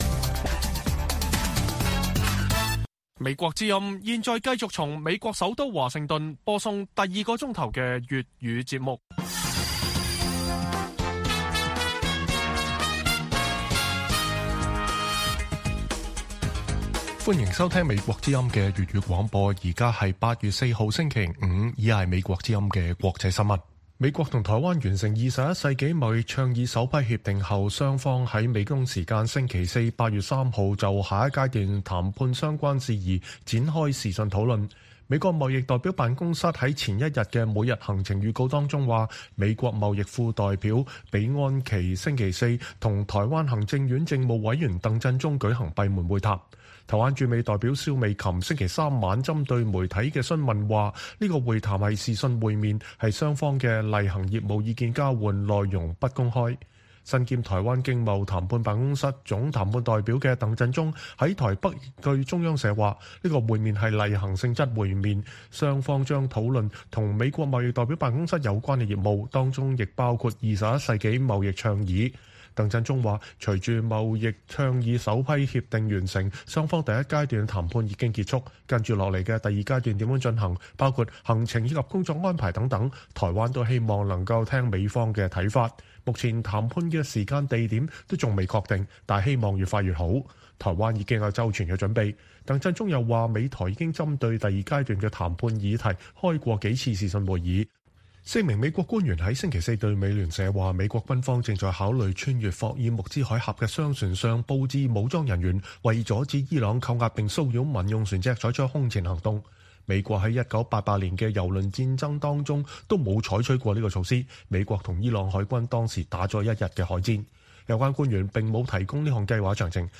北京時間每晚10－11點 (1400-1500 UTC)粵語廣播節目。內容包括國際新聞、時事經緯和社論。